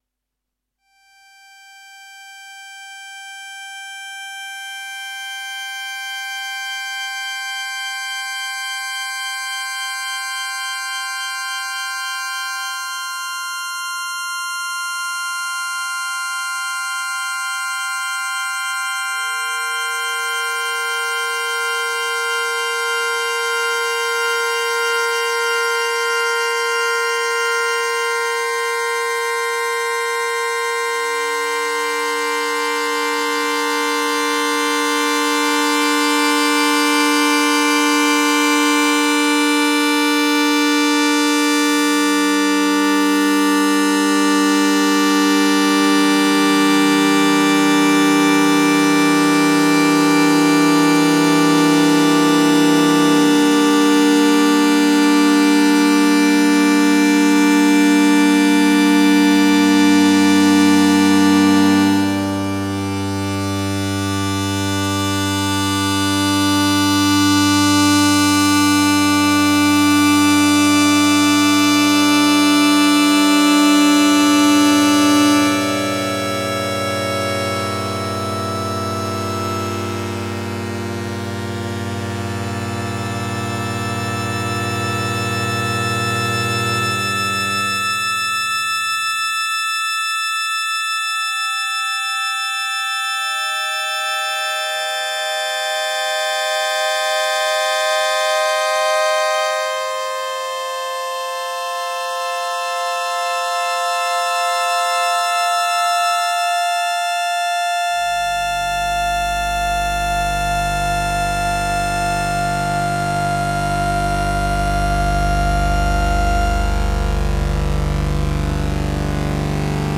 Waldorf Blofeld spectral singing patch